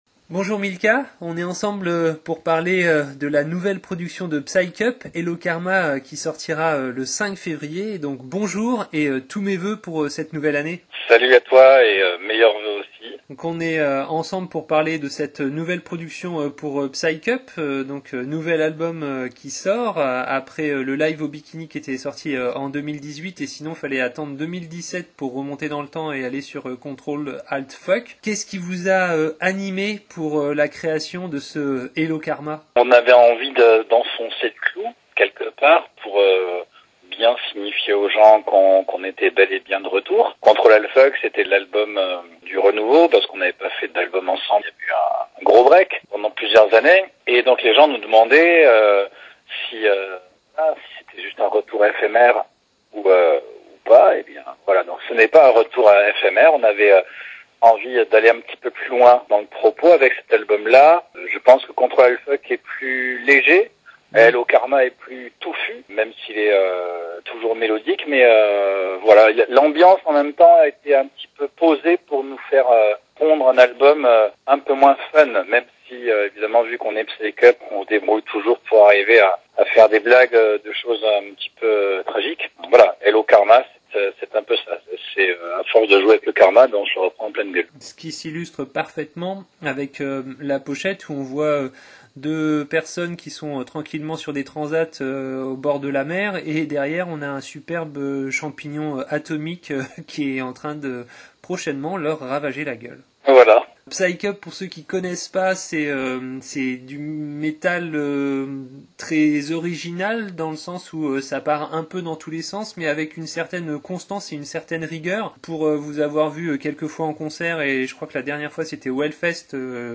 interview enregistrée pour la sortie de l'album Hello Karma le 05.02.2021 : lien vers notre chronique ICI